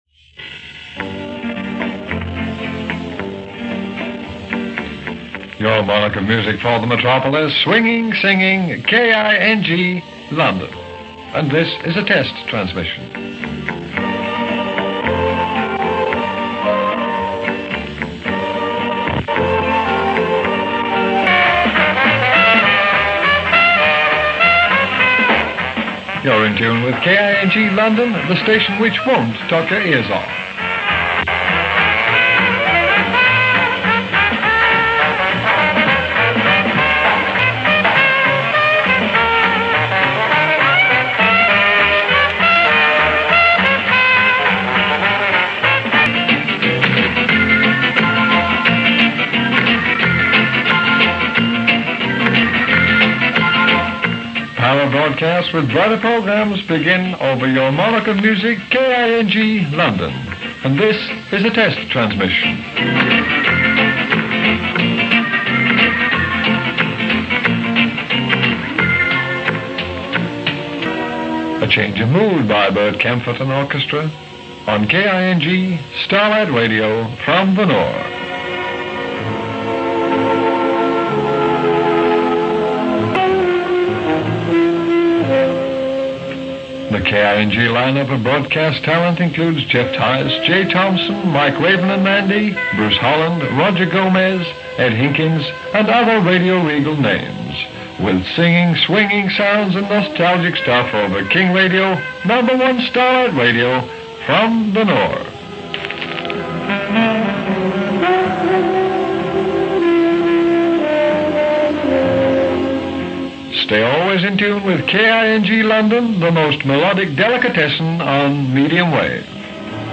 KING Radio  test transmissions.mp3